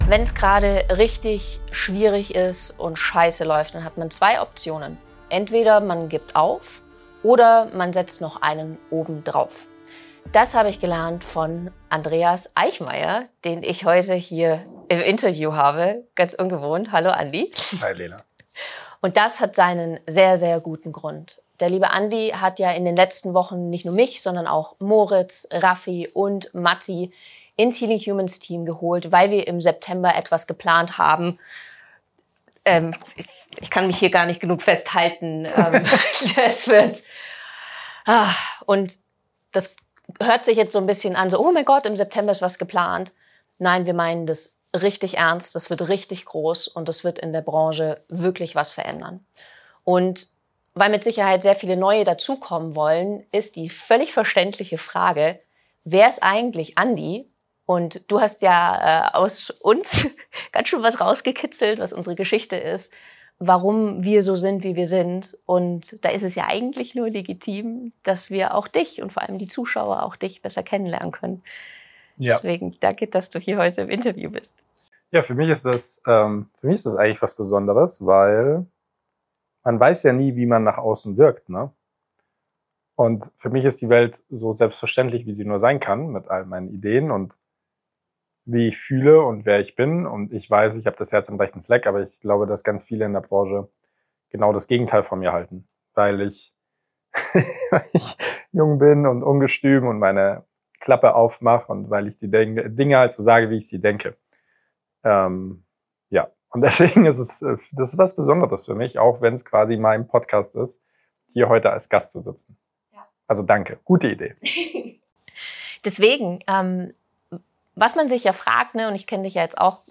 Dieses Interview zeigt die ganze Geschichte: Schmerz, Rebellion, Hoffnung.